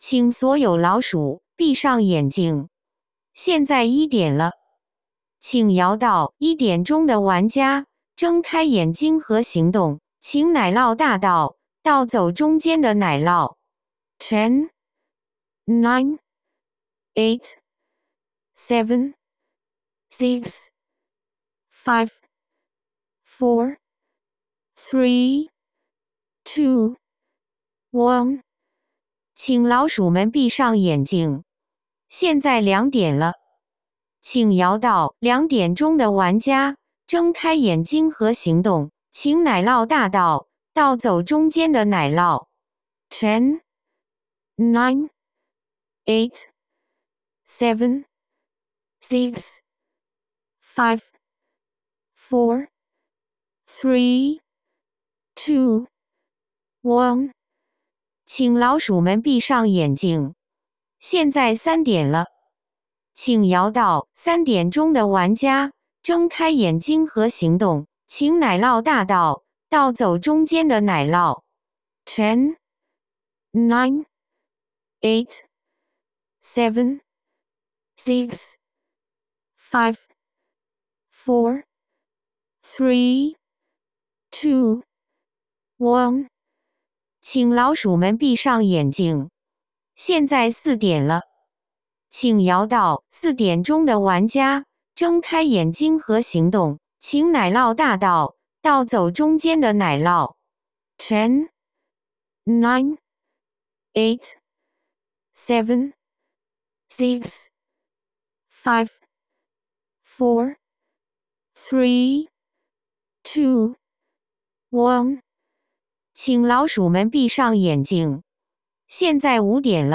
奶酪大盗桌游主持人配音，按 4–8 人局选择，可直接播放与下载。
cheese_thief_5p_host.aiff